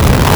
Player_Glitch [74].wav